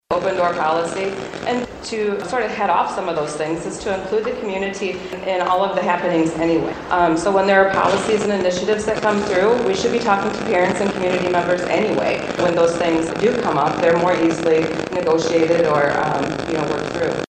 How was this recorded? Colon BOE Interviews Superintendent Candidates